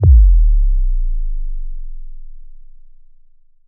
SUB_DROP_DEEP.wav